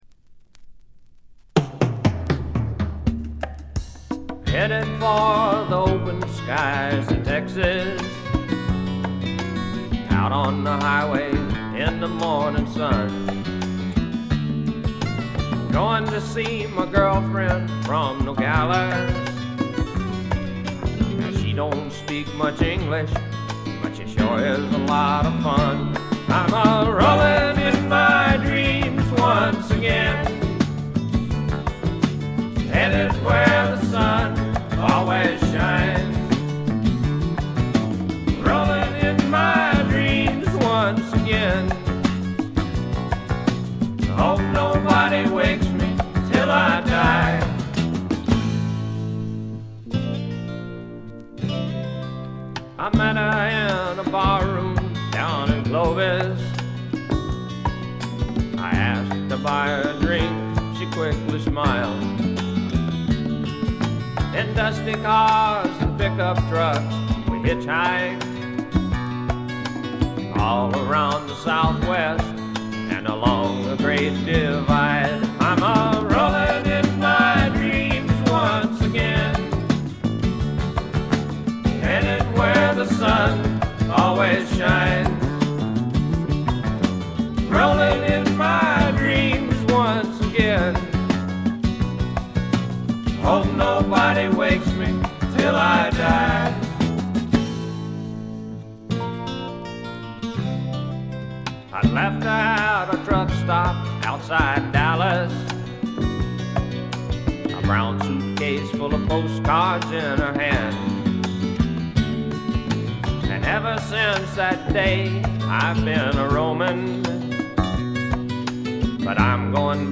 静音部で軽微なチリプチ。
いかにもテキサス／ダラス録音らしいカントリー系のシンガー・ソングライター作品快作です。
ヴォーカルはコクがあって味わい深いもので、ハマる人も多いと思います。
試聴曲は現品からの取り込み音源です。